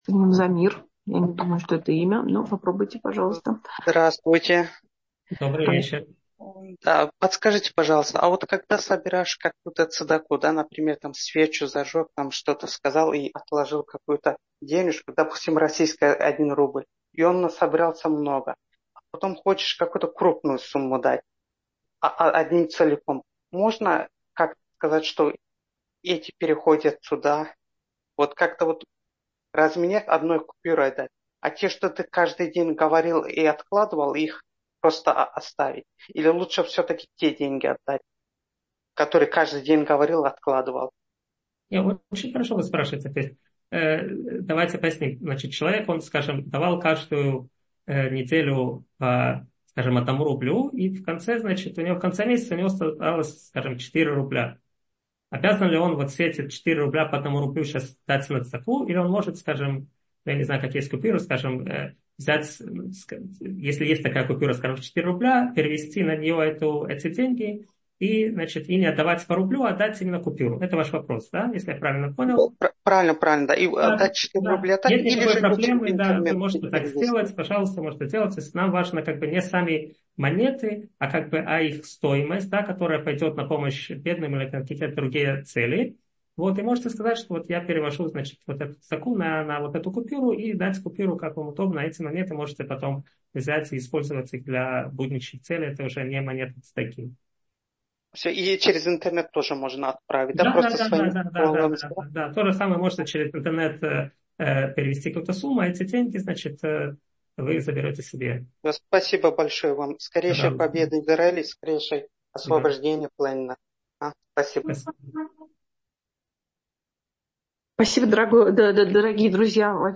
Аудиоуроки